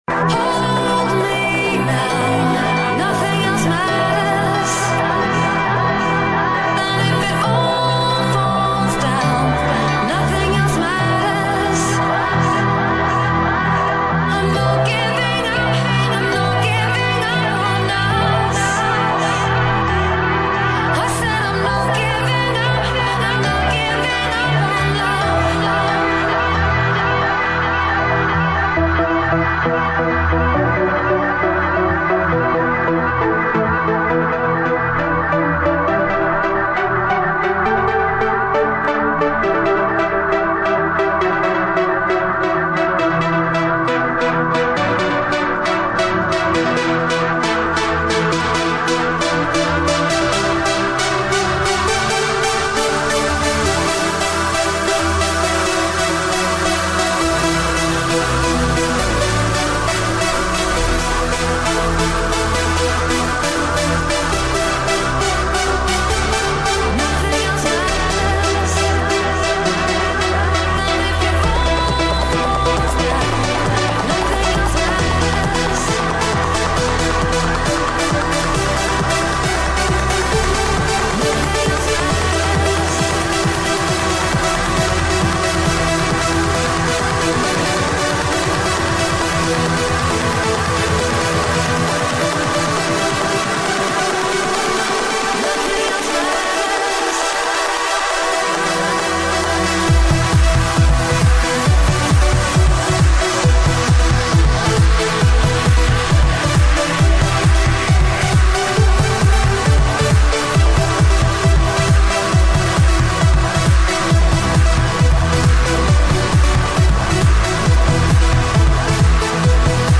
[ TRANCE | PROGRESSIVE HOUSE ]